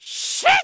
binf_attack_1b.wav